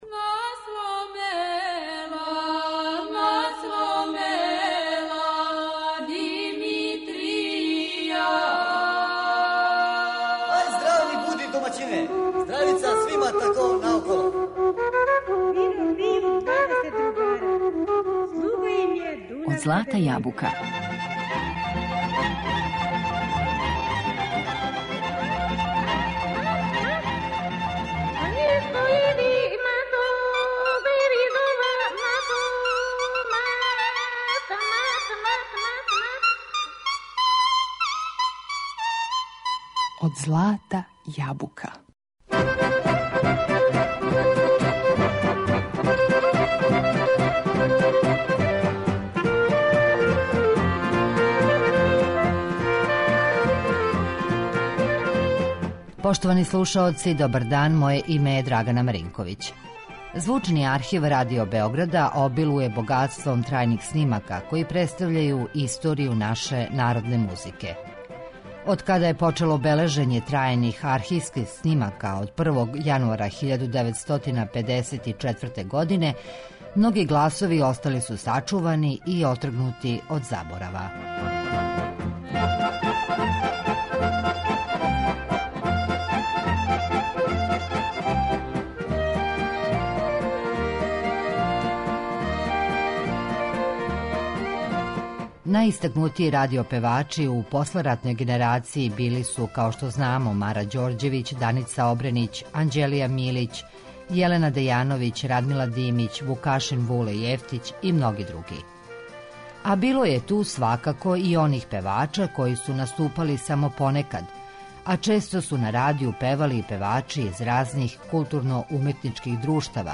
У данашњој емисији слушаћемо ретке, архивске снимке певача који су на Радио Београду крајем педесетих и почетком шездесетих година допринели популаризацији народне музике.
У звучном архиву забележена су њихова извођења уз секстет Душана Радетића и Миодрага Тодоровића Крњевца.